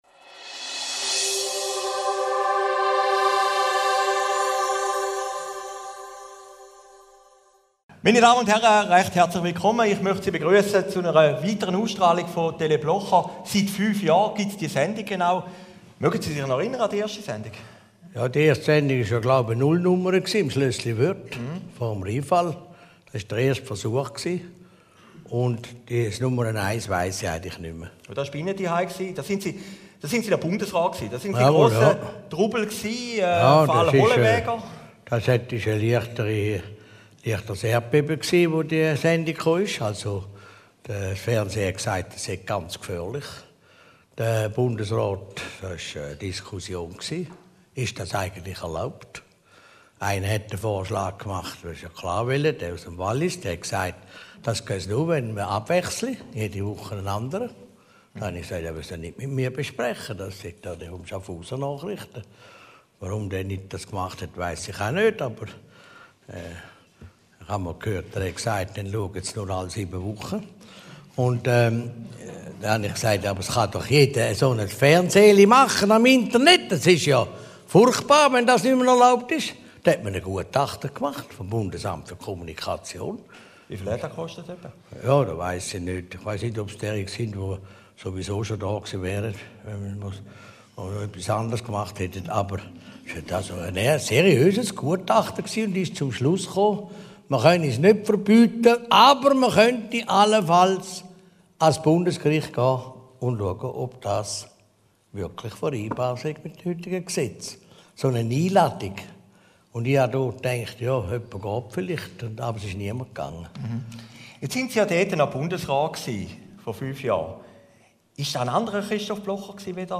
Aufgezeichnet im Zunftsaal zun Kaufleuten, Schaffhausen, 12. September 2012